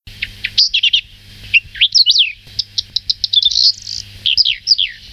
Tarier des prés
Saxicola rubetra
tarier_p.mp3